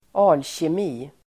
Ladda ner uttalet
Uttal: [altjem'i:]